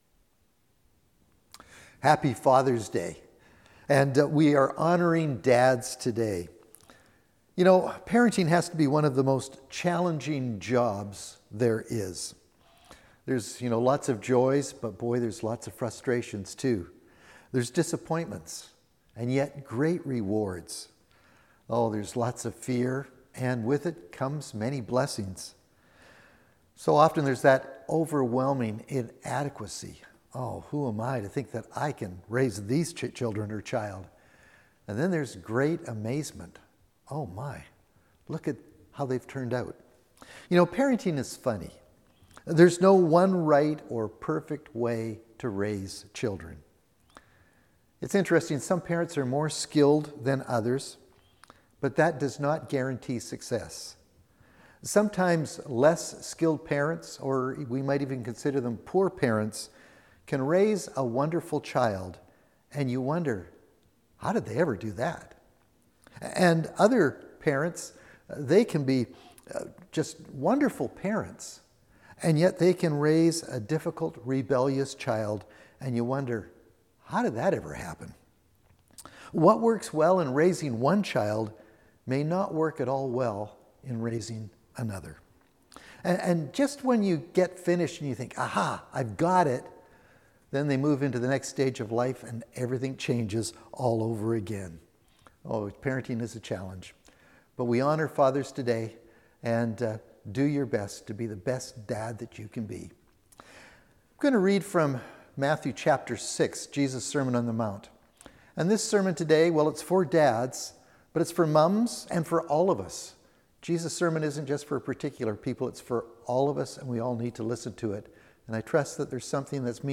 Sermons | Cumberland Community Church